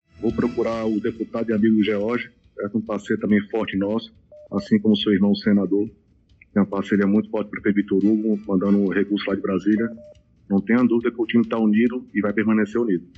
Os comentários foram registrados pelo programa Arapuan Verdade, da Rádio Arapuan FM, de João Pessoa, nesta quarta-feira (18/10).
sonora-andre-coutinho-cabedelo.mp3